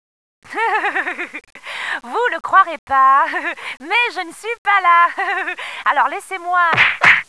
Messages d'imitations 1: